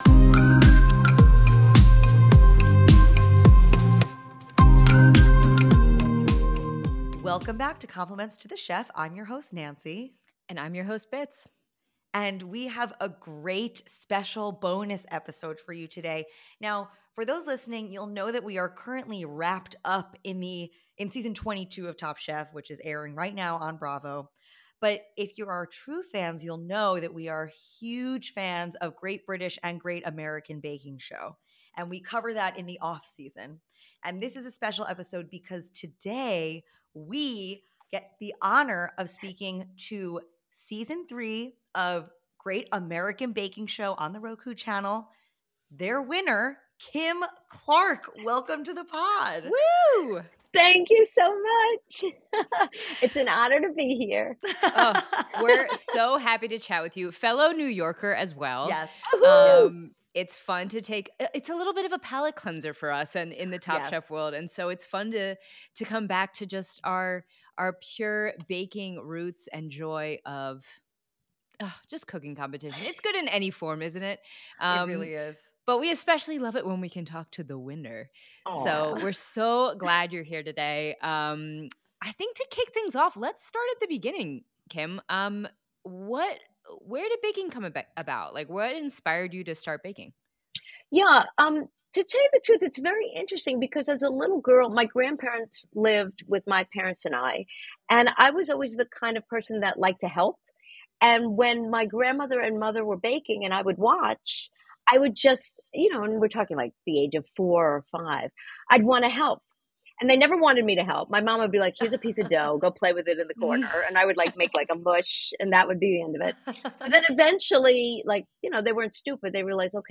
Tune into this fun interview!